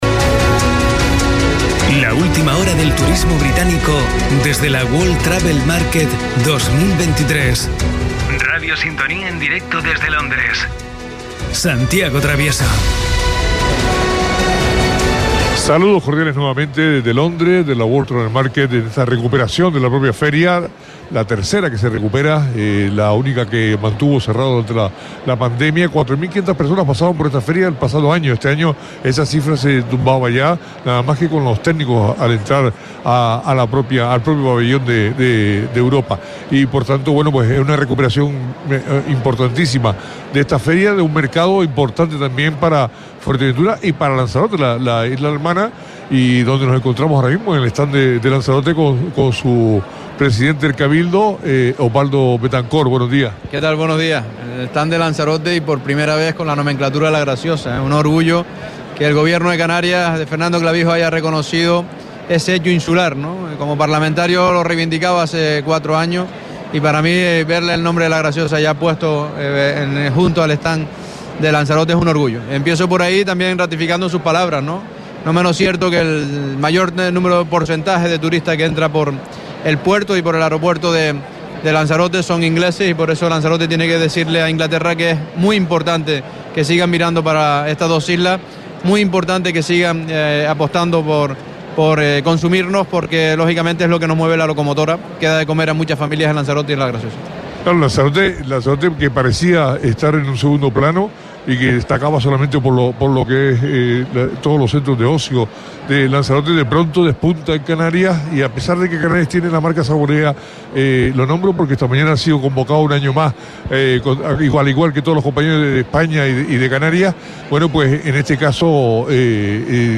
Nos encontramos, en la World Travel Market, con Oswaldo Betacort, presidente del Cabildo de Lanzarote
Entrevistas